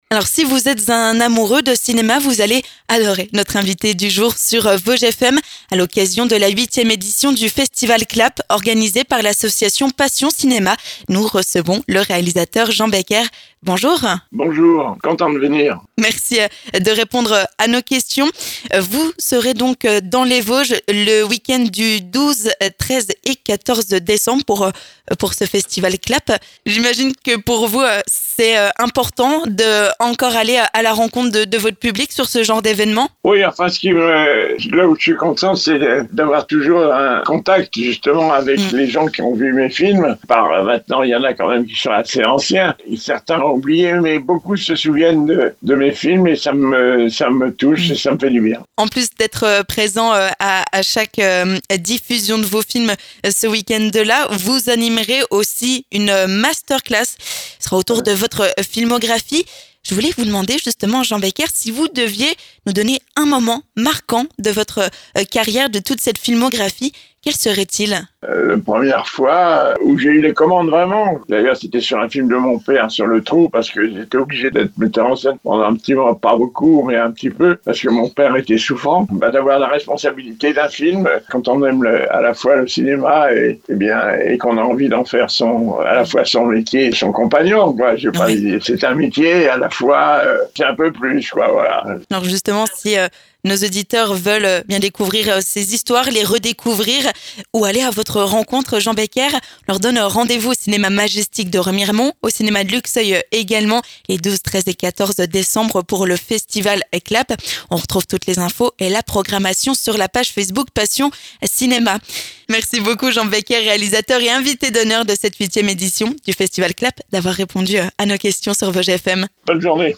3. L'invité du jour
A cette occasion, Jean Becker est notre invité du jour sur Vosges FM !